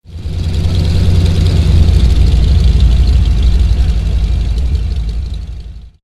biplane.mp3